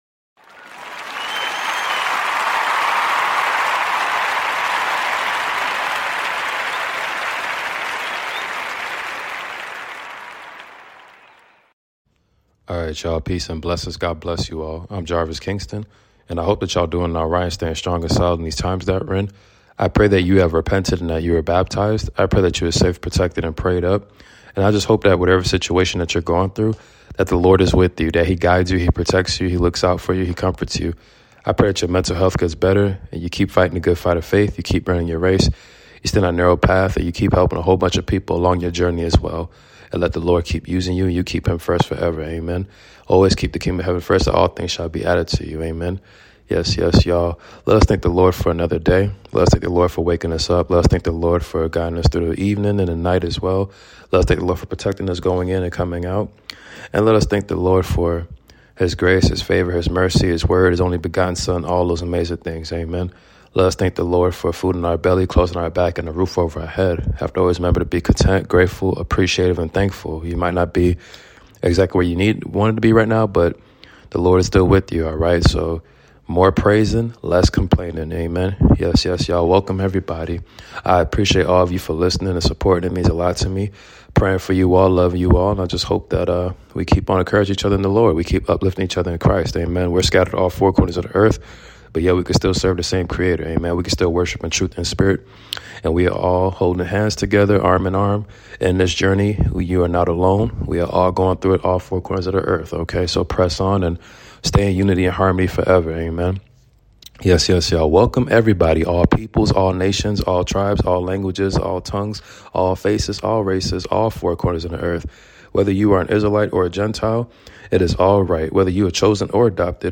Psalm 23 Reading